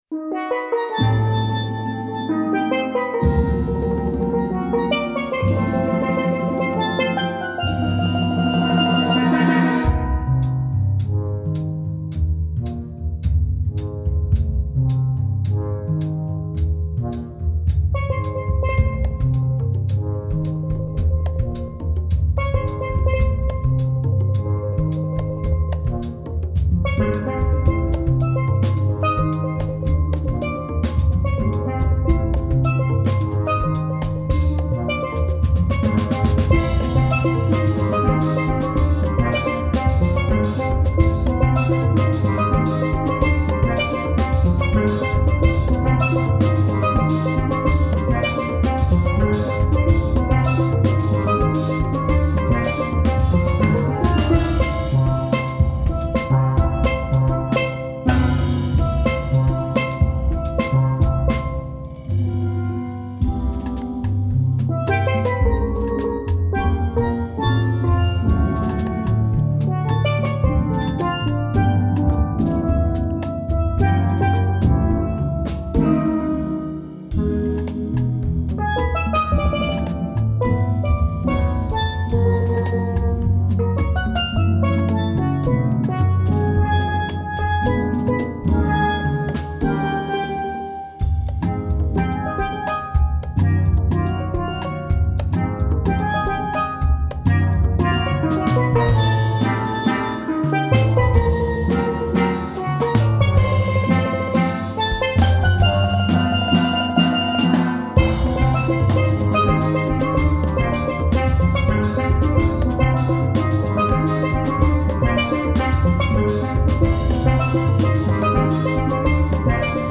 Voicing: Steel band